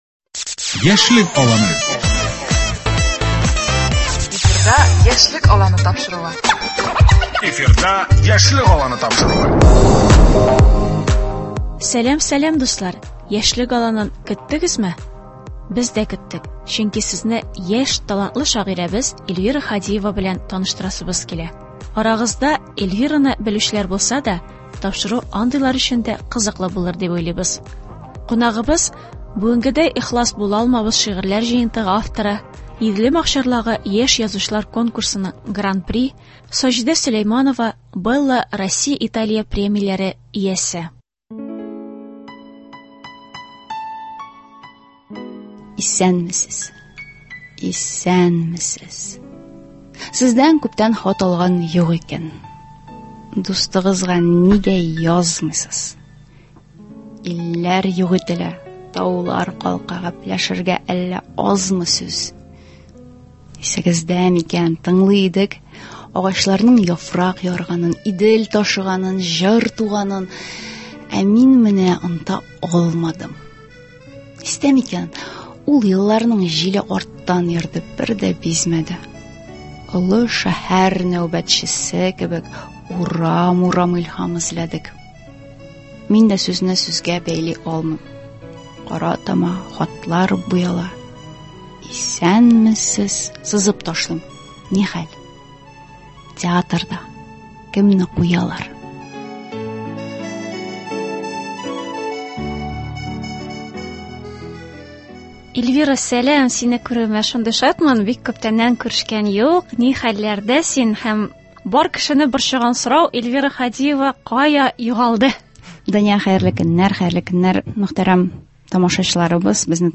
Студия кунагы